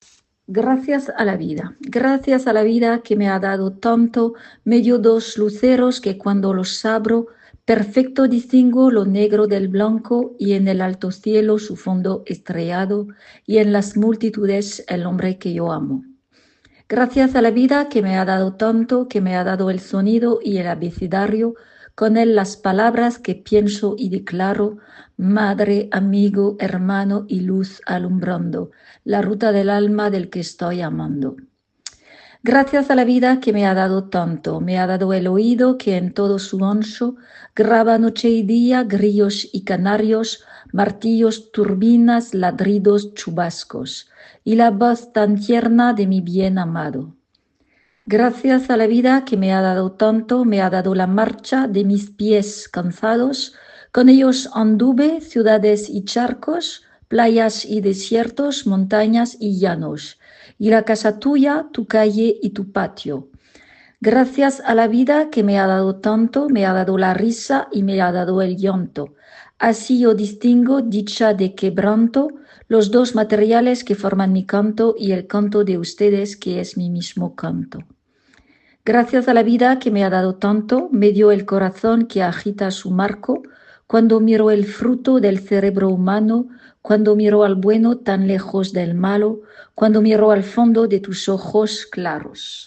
prononciation-de-Gracias
prononciation-de-gracias.mp3